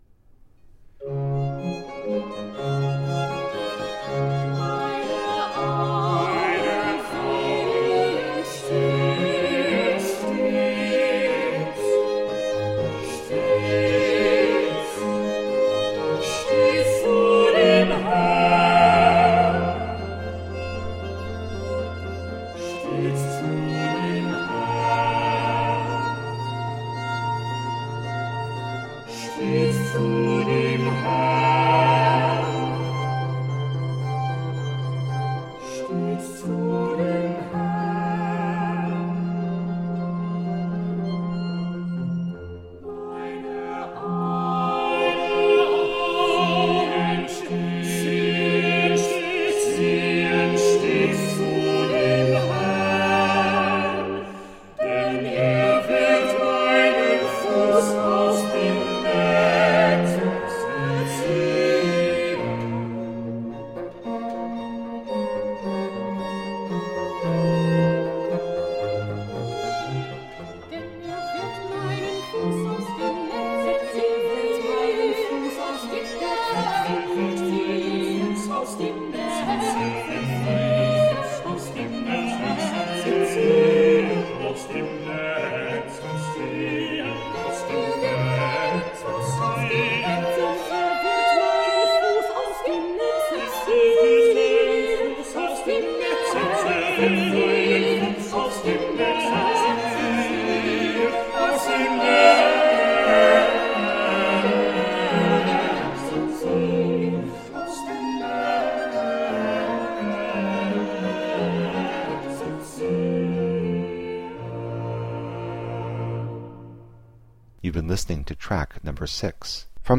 Baroque instrumental and vocal gems.